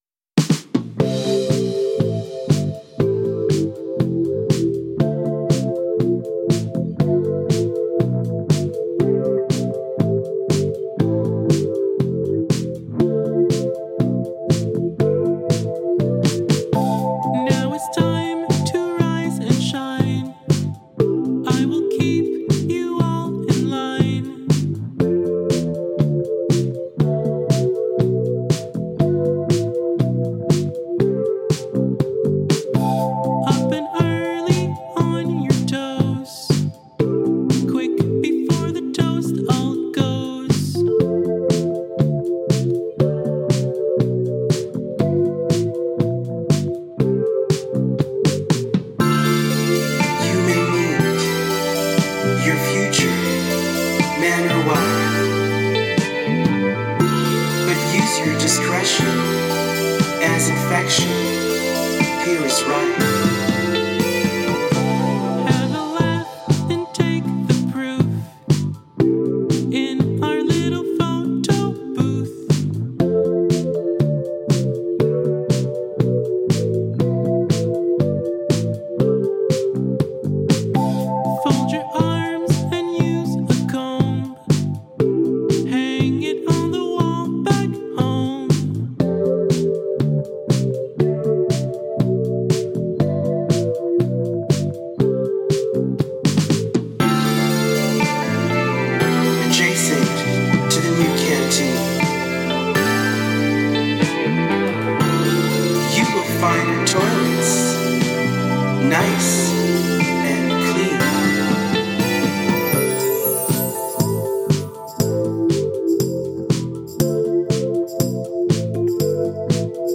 dreamy